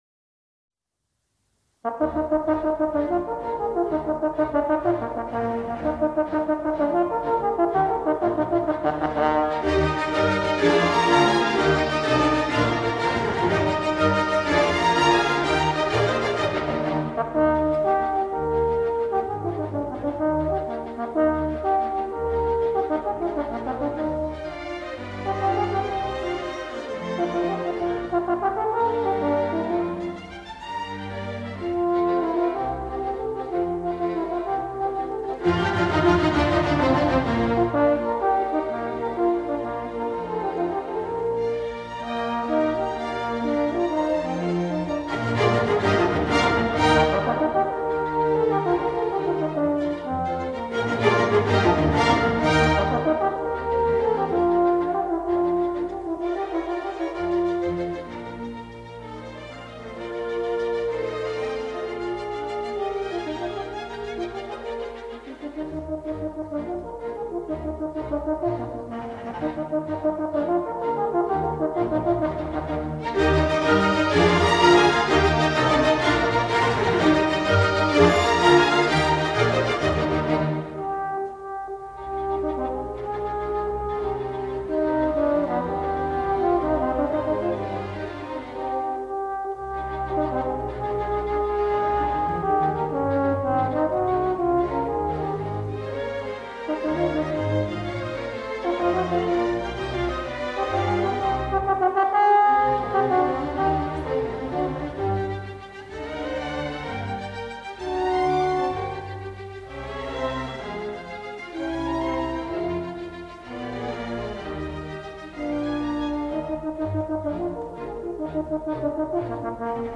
Concierto para trompa en